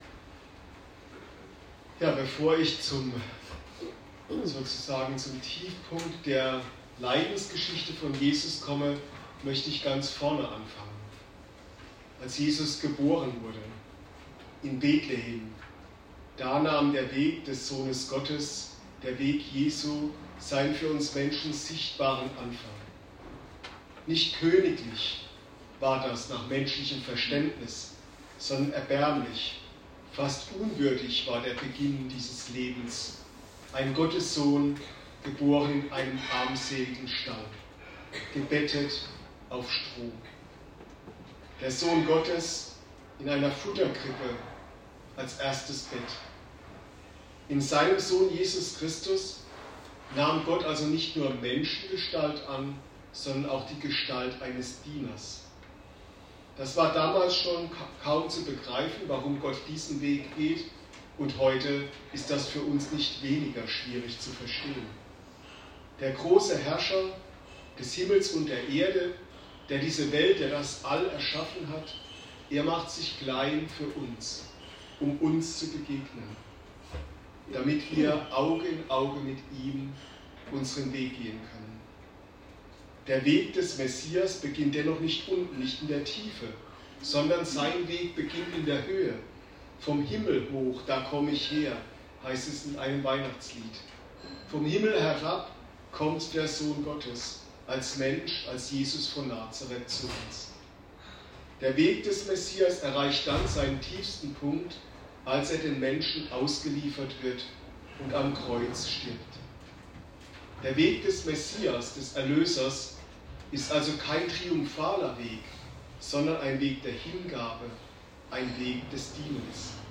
Für alle, die nicht persönlich dabei sein konnten oder den Gottesdienst noch einmal erleben möchten, steht die Predigt als Audiodatei unten zur Verfügung.
Gottesdienste im AWO-Seniorenzentrum